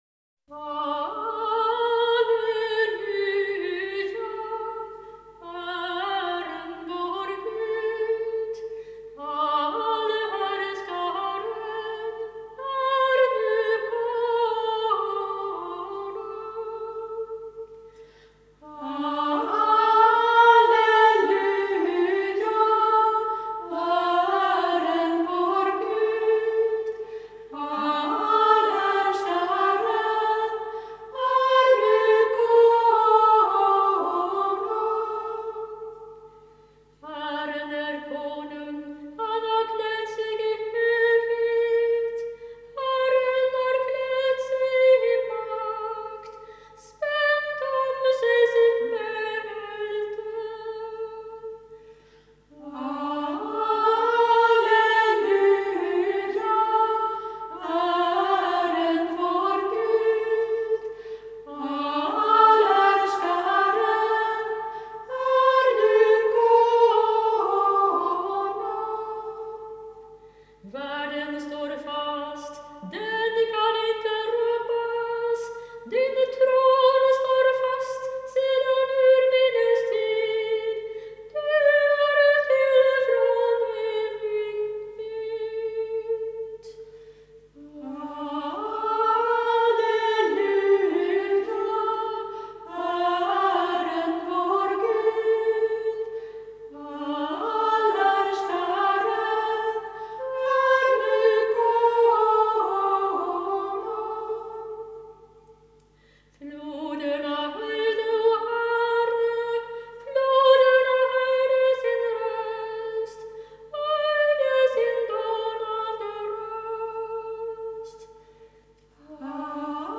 systrar tillsammans med vänner